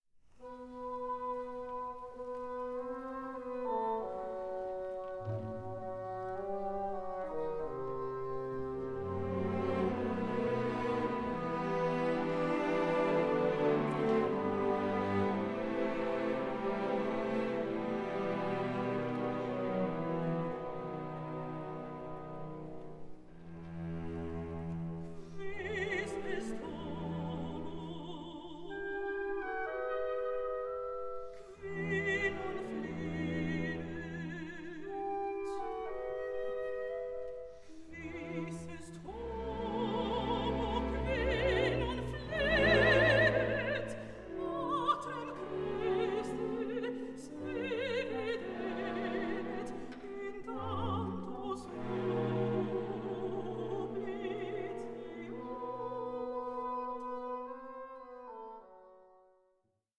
for soloists, choir and orchestra
Live recording